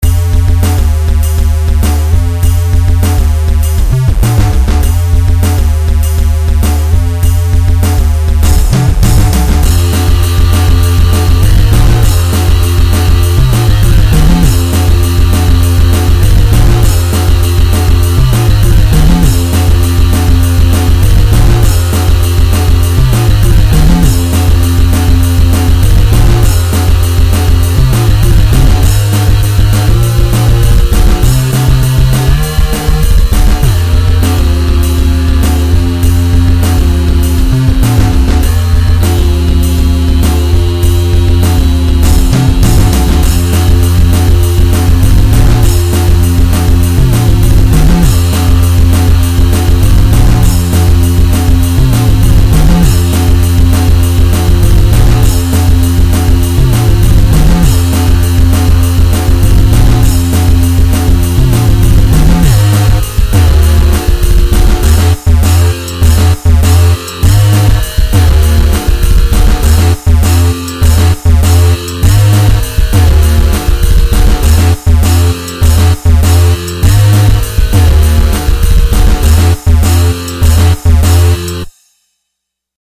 Flstudio remix